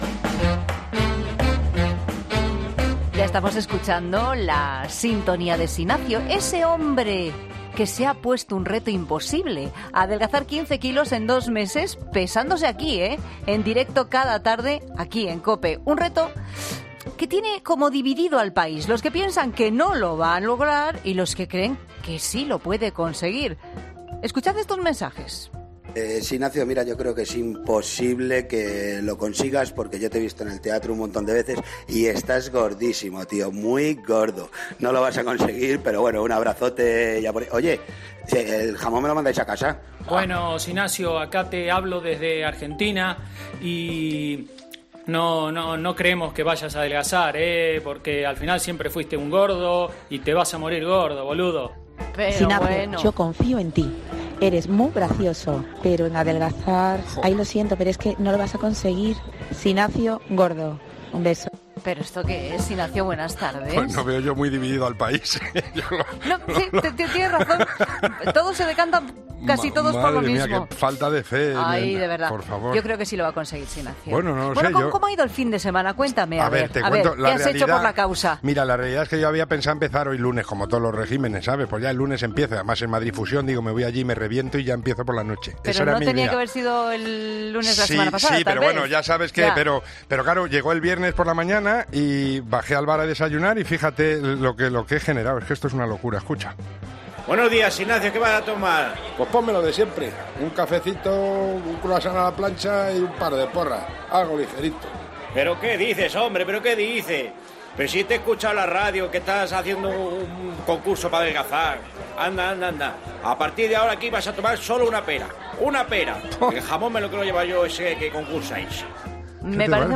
Antes de descubrir el peso, escuchamos diferentes mensajes de oyentes.